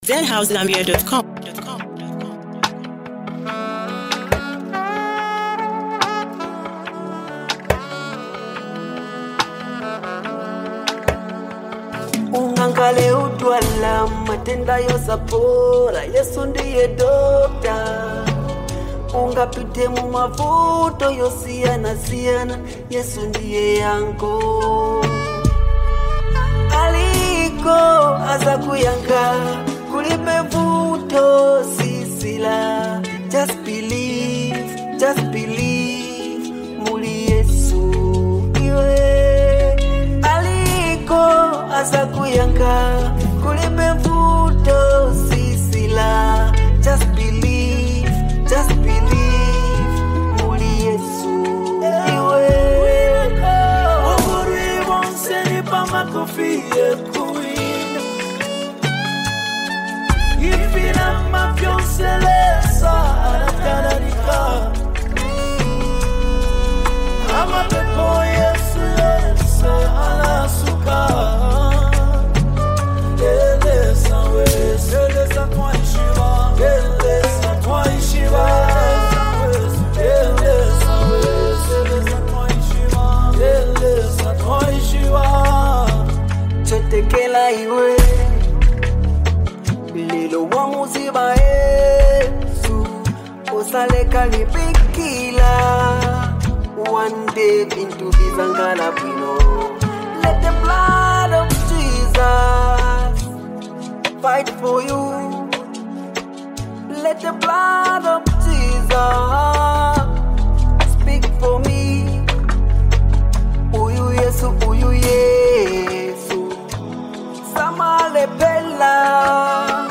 powerful gospel anthem